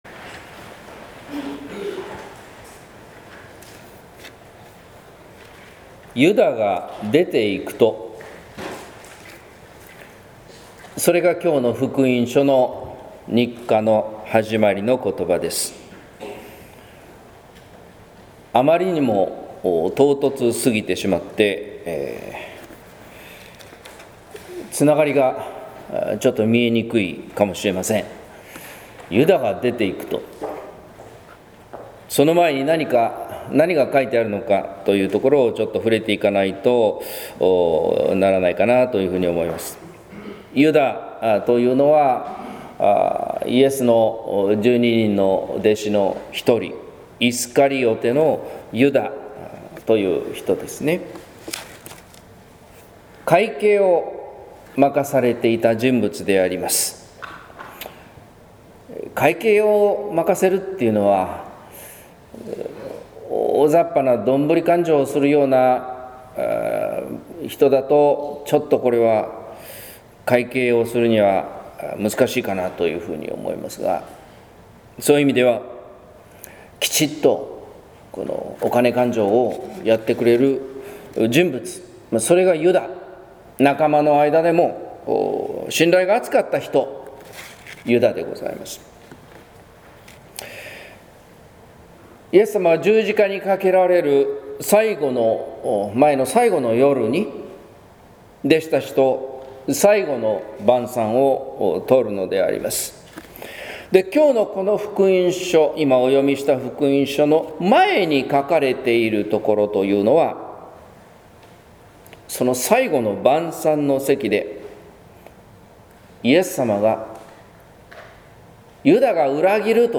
説教「誉れは人のためならず」（音声版）